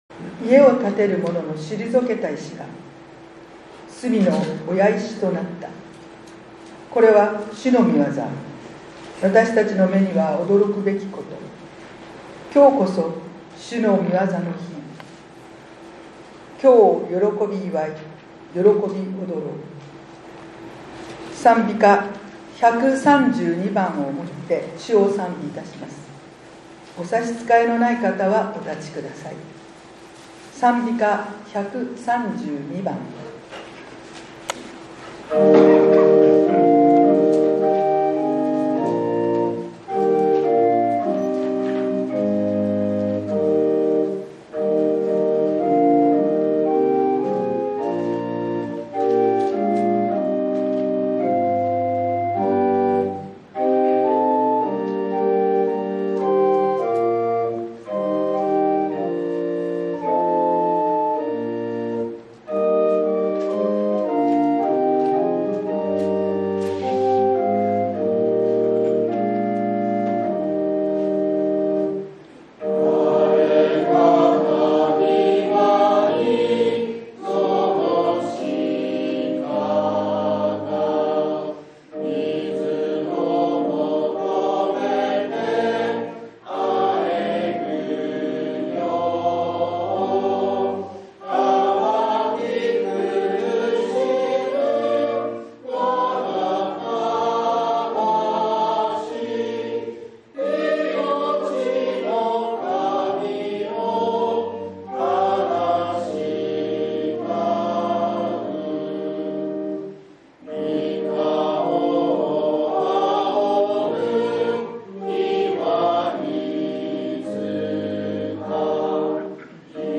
１月１２日（日）主日礼拝